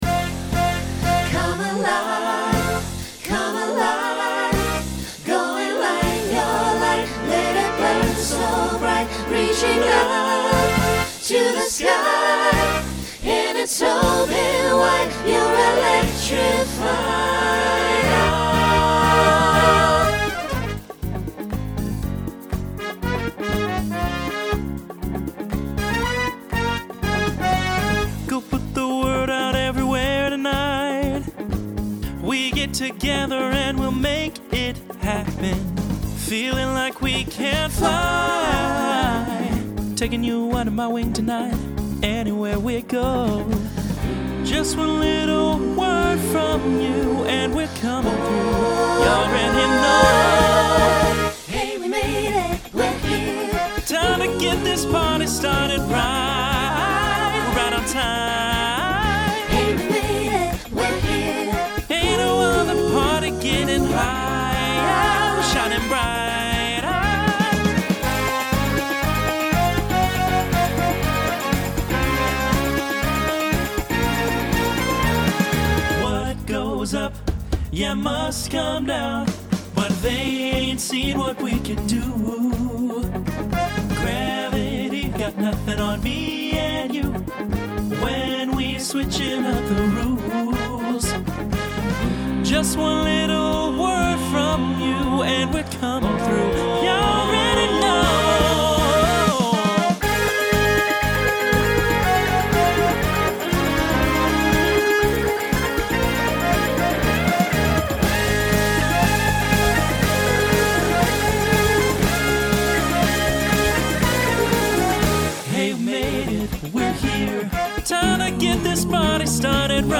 Starts SATB and ends TTB.
Genre Broadway/Film
Voicing Mixed